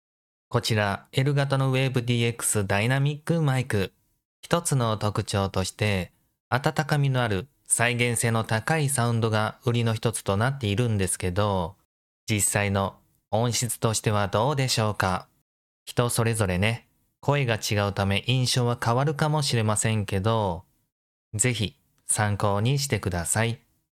• ダイナミックマイク
• 温かみのある再現性の高いサウンド特性
Elgato Wave DX ダイナミックマイク！実際に音声を録音してみる
■ マイクとの距離は約15cmほど
優しく感じる音質かなと好印象です。
トゲトゲしていない感じかなぁ～～
elgato-wavedx-review-fallout30.mp3